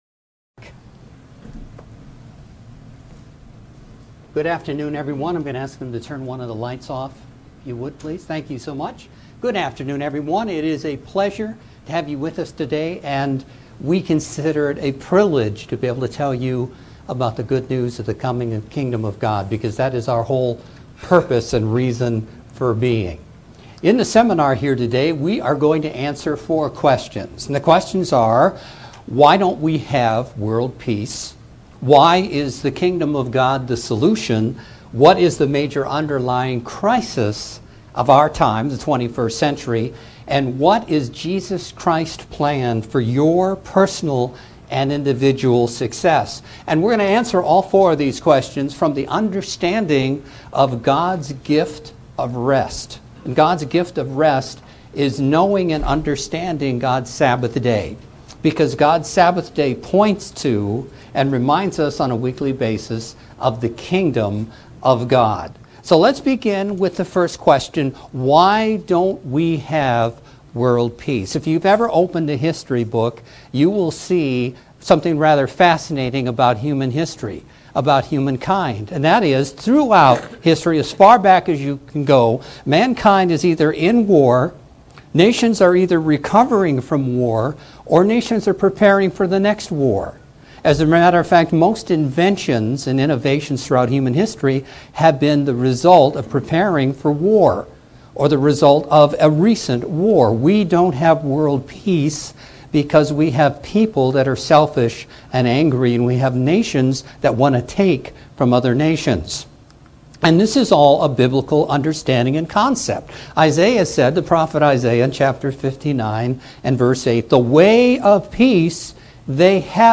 In this Kingdom of God seminar, we take a look at how our Great God has laid out His plans for mankind. Our loving Creator has arranged signs to show the future He has for Mankind.